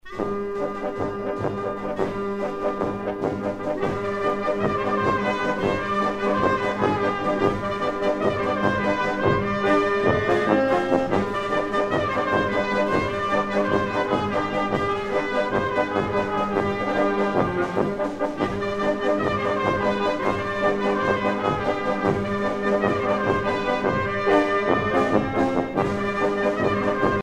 danse : horo (Bulgarie)
Pièce musicale éditée